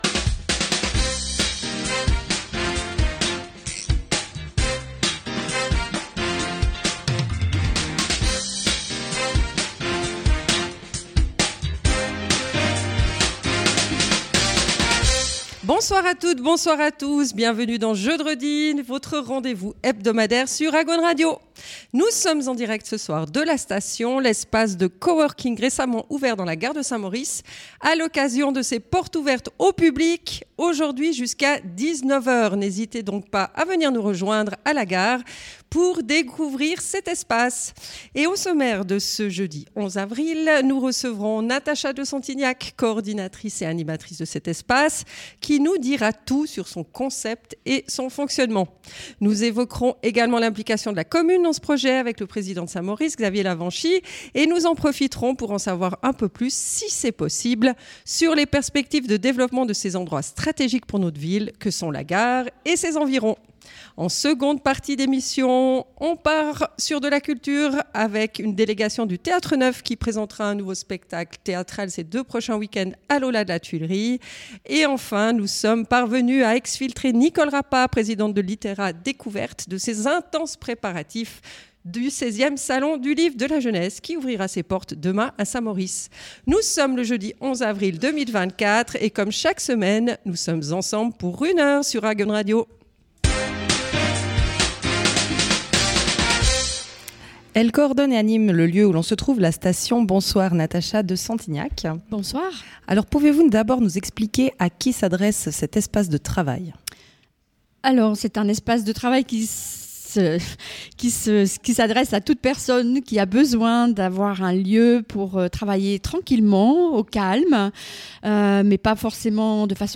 En direct de _La Satation, espace de coworking de la gare de St-Maurice :